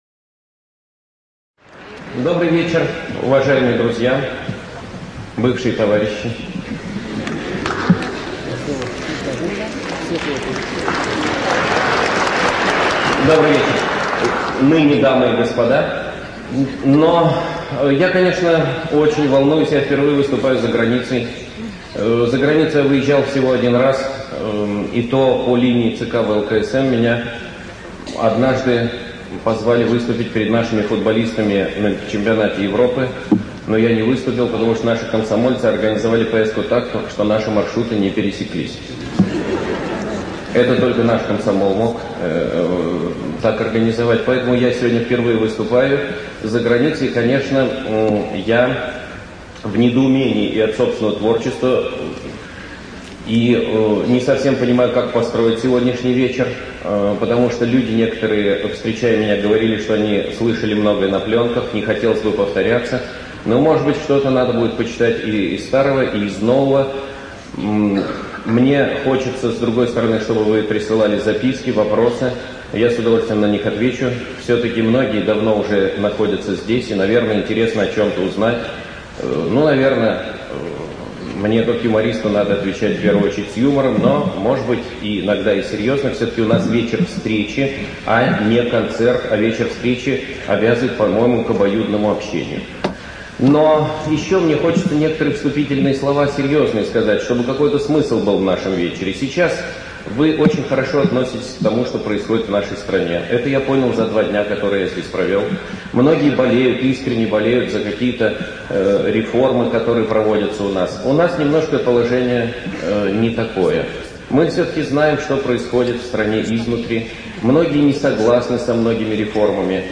ЧитаетАвтор
ЖанрЮмор и сатира, Телевизионные программы